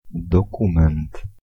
Ääntäminen
US
IPA : /ˈdɑkjʊmənt/